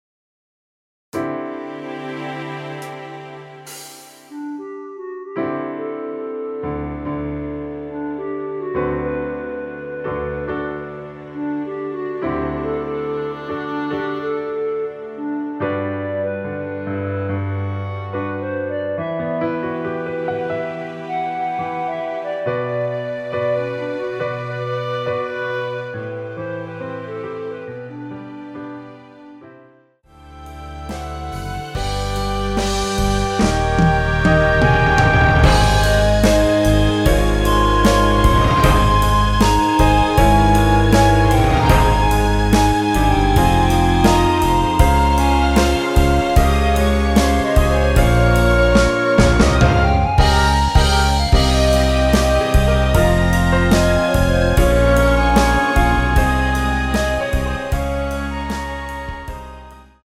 여자키에서 (-2)내린멜로디 포함된MR입니다.(미리듣기 참조)
앞부분30초, 뒷부분30초씩 편집해서 올려 드리고 있습니다.
(멜로디 MR)은 가이드 멜로디가 포함된 MR 입니다.